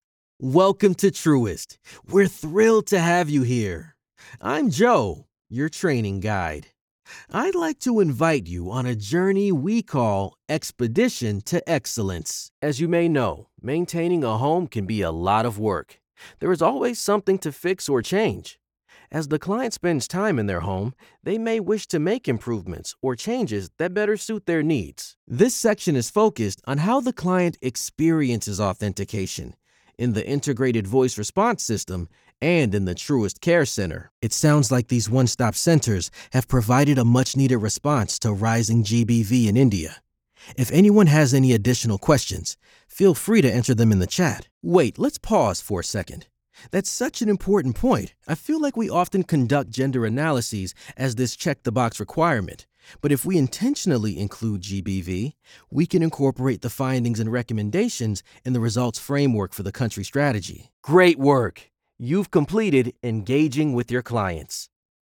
English (American)
Commercial, Urban, Cool, Friendly, Warm, Natural
E-learning
If you’re looking for a grounded, believable male voice for your project, look no further!